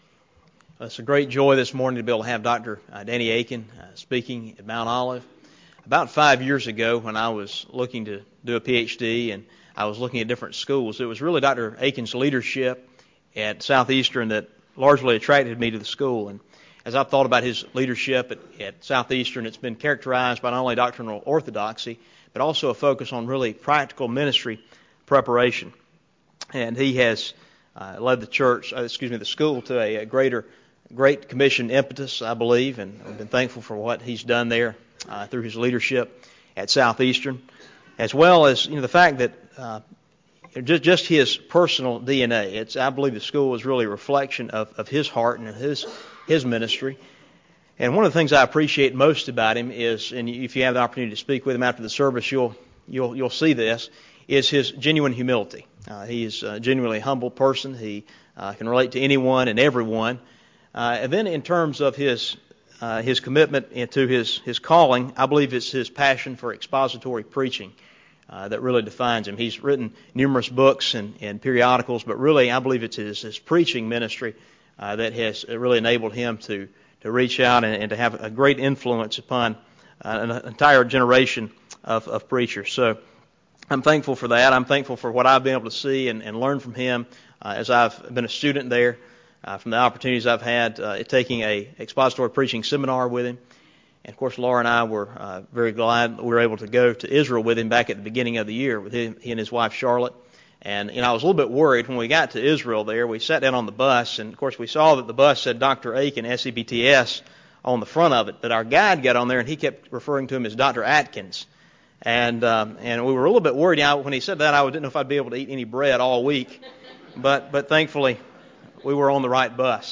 Church Revitalization Conference Sunday Morning